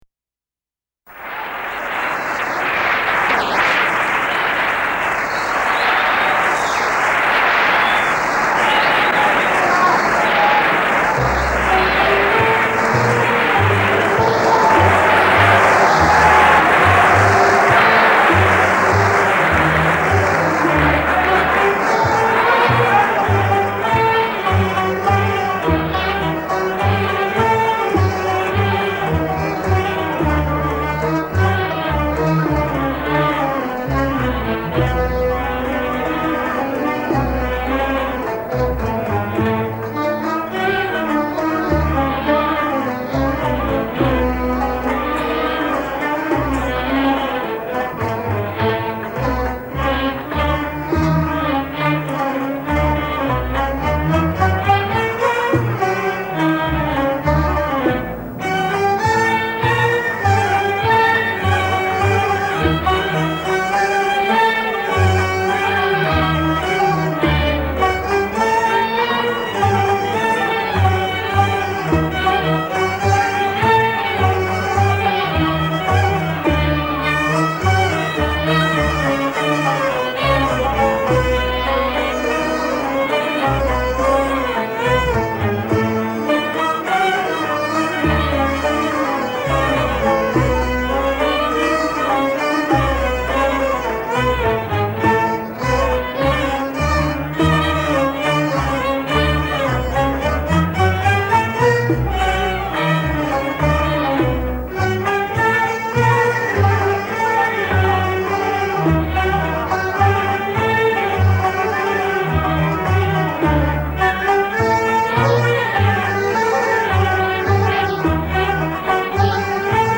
melodies go down to 6th underneath tonic
Maqam Saba